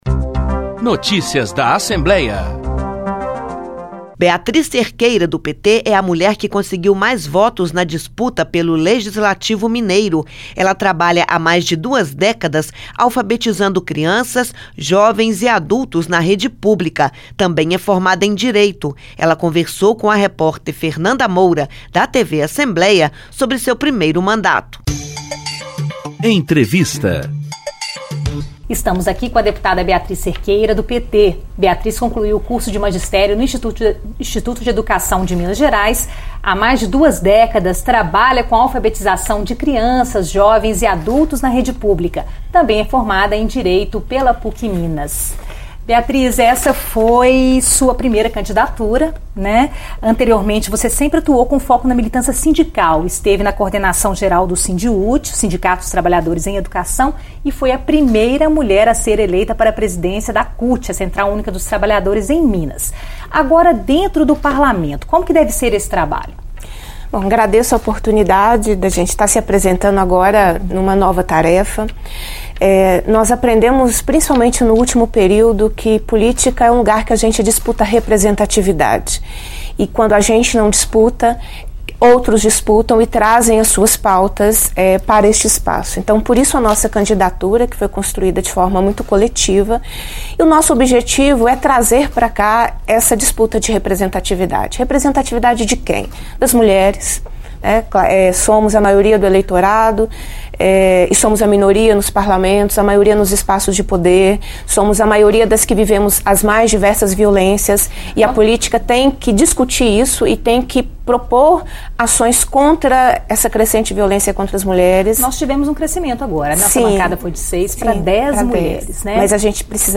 Beatriz Cerqueira foi a primeira entrevistada, na série de programas que começou nesta terça-feira (9).